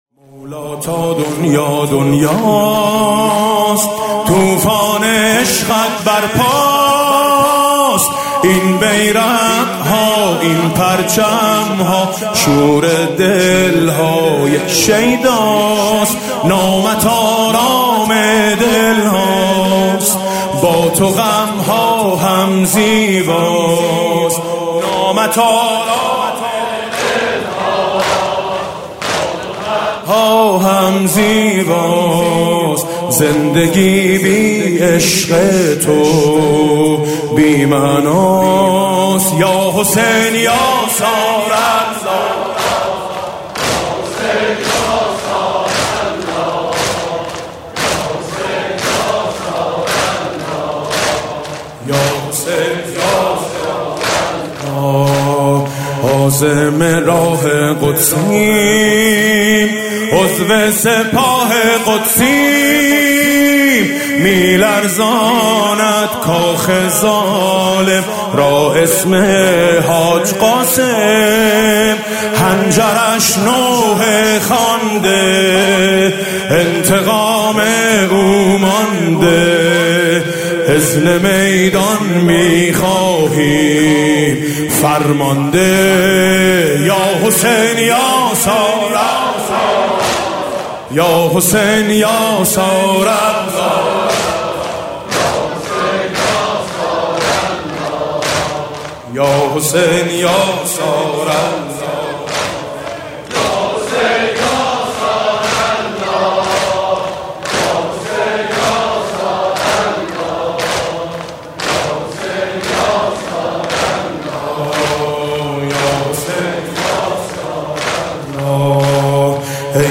مداحی شب هشتم محرم ۹۹
بخش ۱ : سلام آقا، غریب آشنای طوس – زمزمه